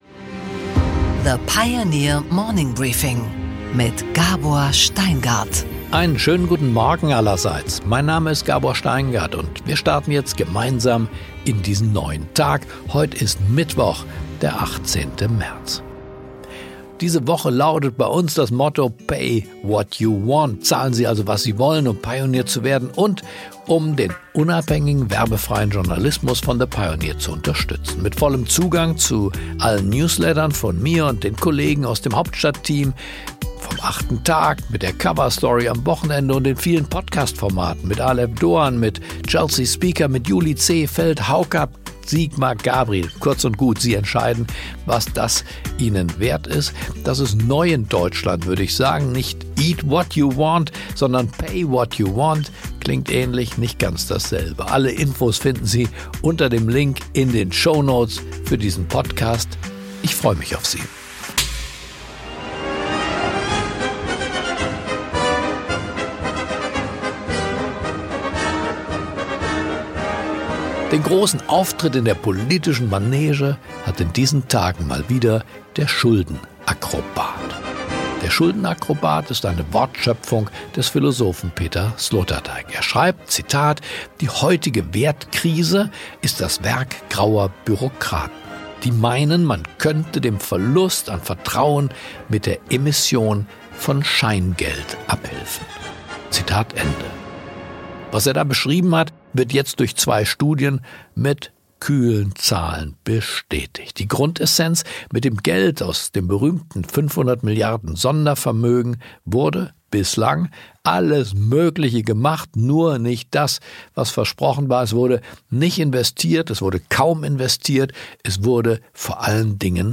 Gabor Steingart präsentiert das Morning Briefing.
Ein Kommentar von Gabor Steingart.